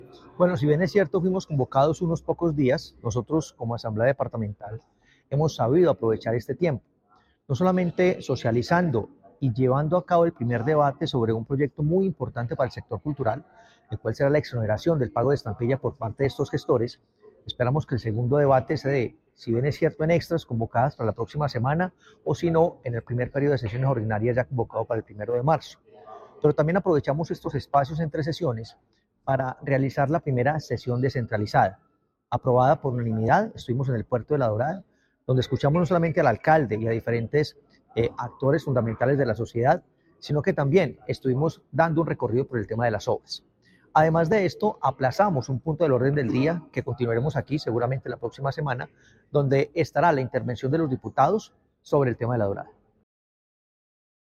Hernán Alberto Bedoya, presidente de la Asamblea Departamental.